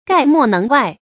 概莫能外 gài mò néng wài
概莫能外发音